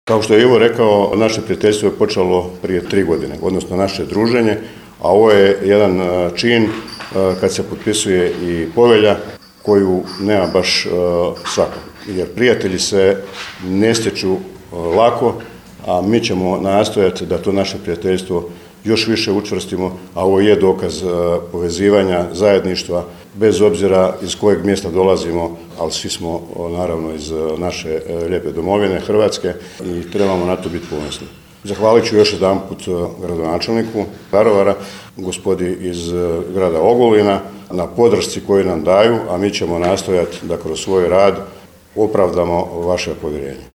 Povodom obilježavanja 30. obljetnice vojno-redarstvene akcije Bljesak u Velikoj vijećnici zgrade Gradske uprave organizirano je  potpisivanje Povelje o prijateljstvu i suradnji između Udruge HVIDR-a Daruvar i Udruge  HVIDR-a Ogulin.